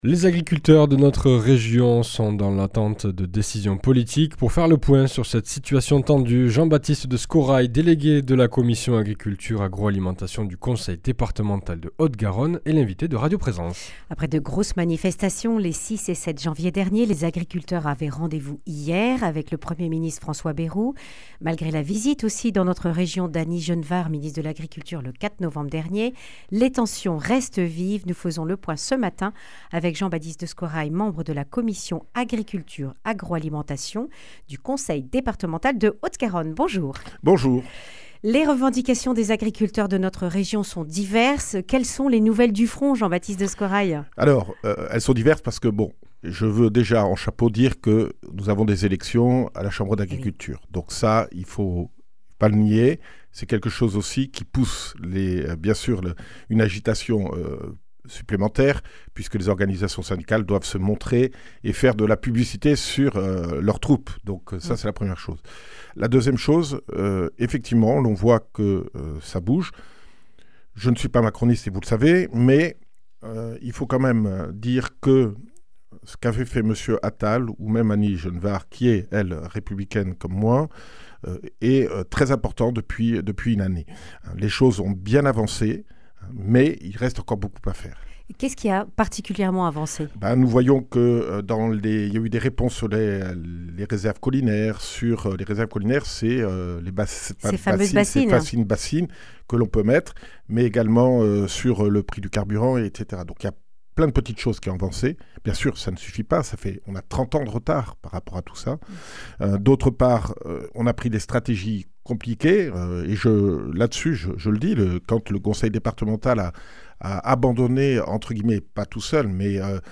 A l’approche des élections au sein des Chambres d’agriculture, les agriculteurs haussent le ton. Après leur rencontre le 13 janvier avec le Premier ministre François Bayrou, ils scruteront le 14 après-midi son discours de politique générale. Pour faire le point sur cette situation tendue, Jean-Baptiste de Scorraille, membre de la commission Agriculture - Agroalimentation du Conseil départemental de Haute-Garonne est l’invité de radio Présence.
Le grand entretien